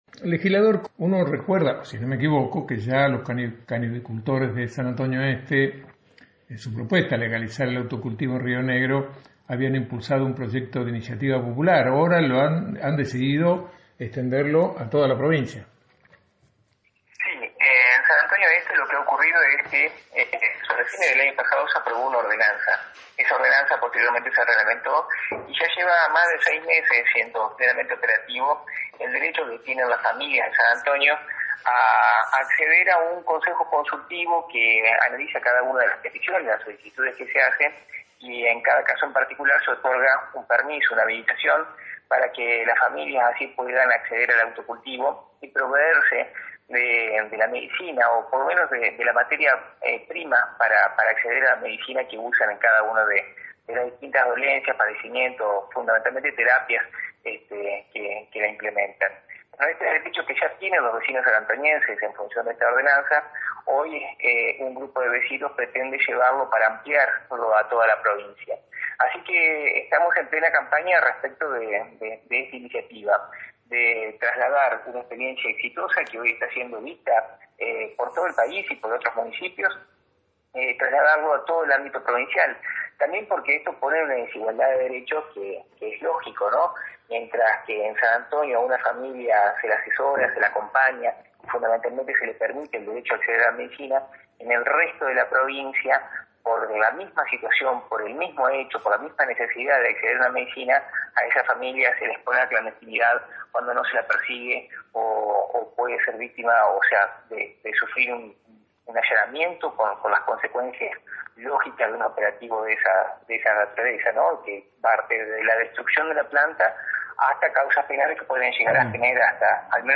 para escuchar la entrevista recordá pausar el reproductor de radio en vivo